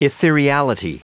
Prononciation du mot ethereality en anglais (fichier audio)
Prononciation du mot : ethereality